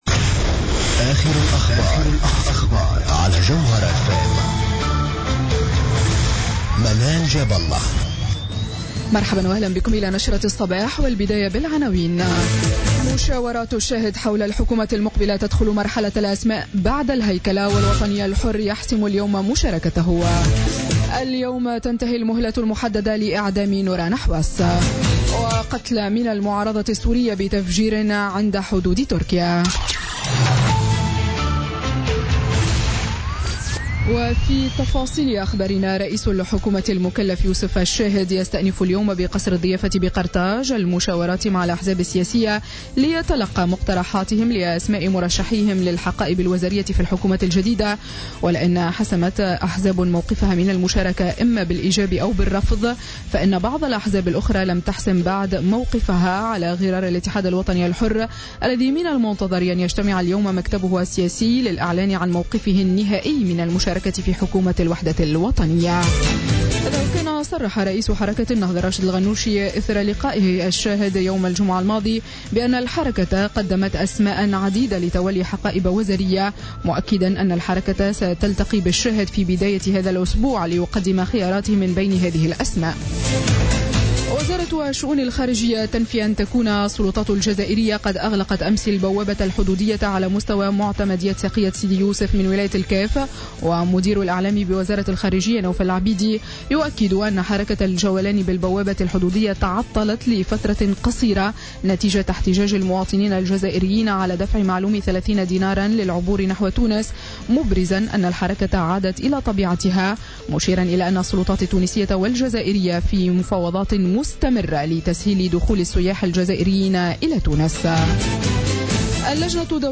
نشرة أخبار السابعة صباحا ليوم الاثنين 15 أوت 2016